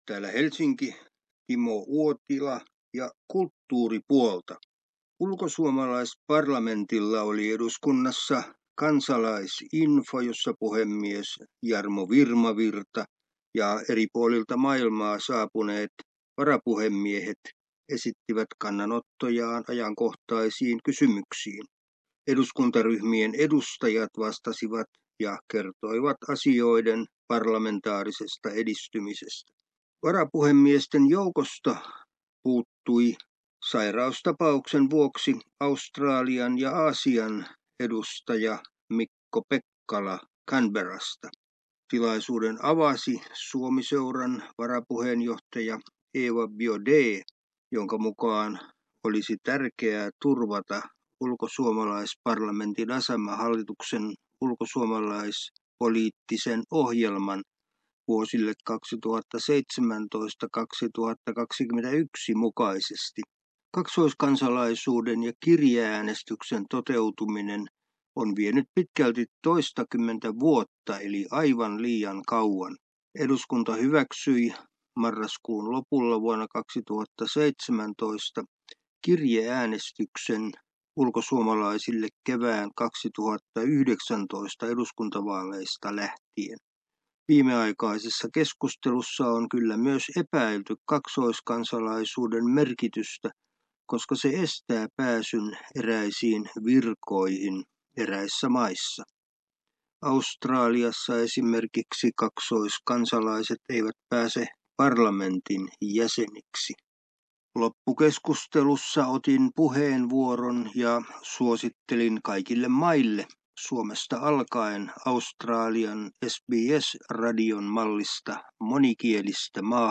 kulttuuriraportti Suomesta